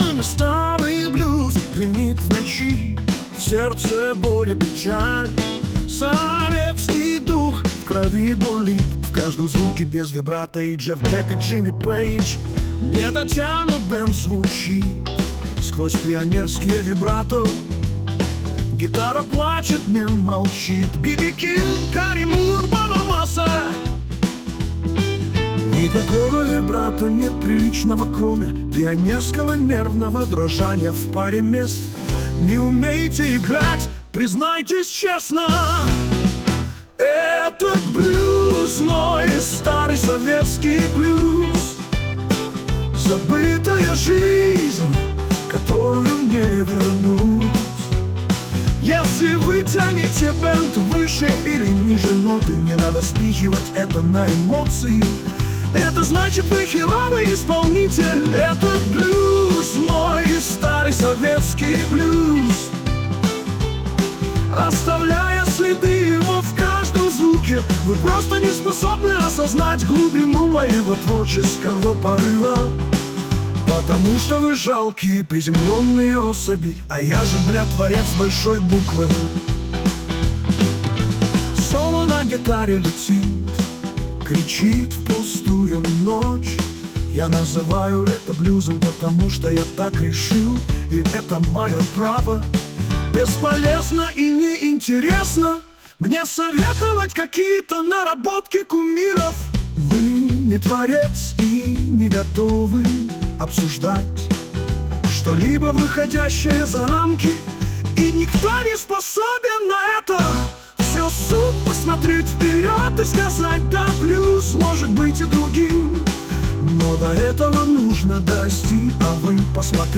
Pleasnst DejaVu - гитарный блююз
а что если ai напишет блюз из цитат этой темы? проверим: Вложения Soviet Blues (1).mp3 Soviet Blues (1).mp3 5,5 MB · Просмотры: 911 Soviet Blues(2).mp3 Soviet Blues(2).mp3 5,5 MB · Просмотры: 874